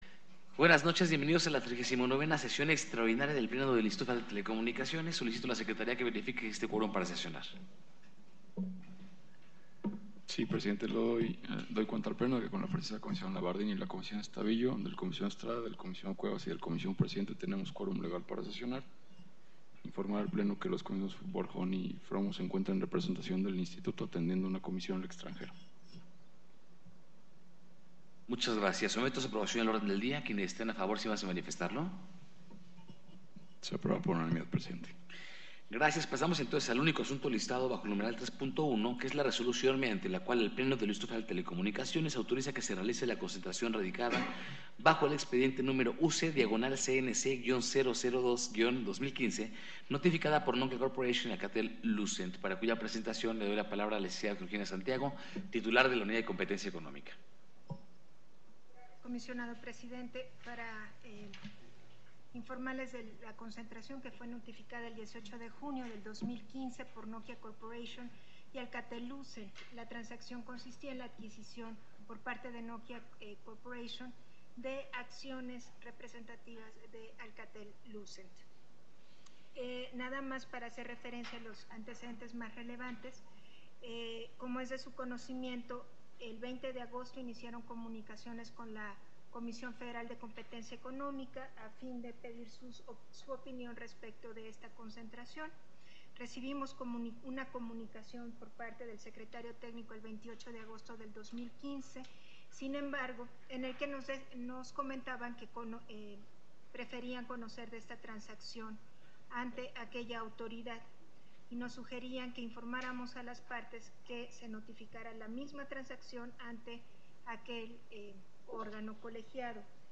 Audio de la sesión